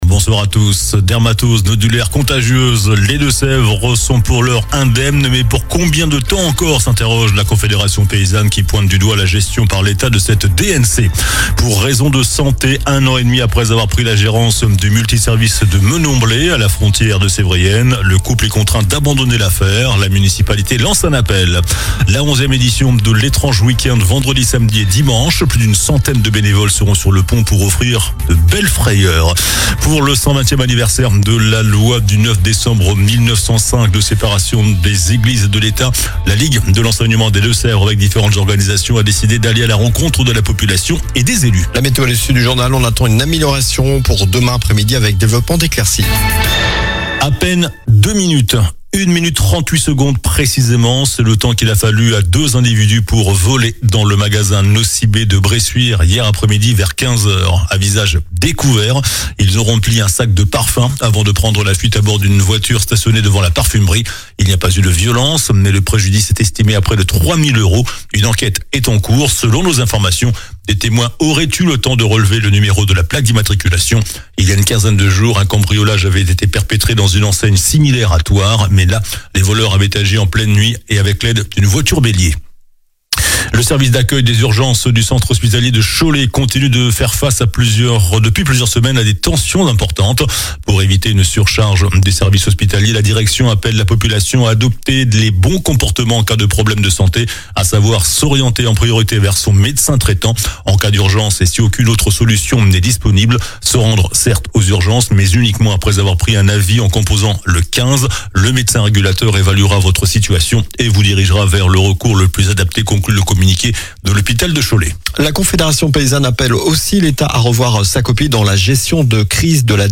JOURNAL DU MERCREDI 29 OCTOBRE ( SOIR )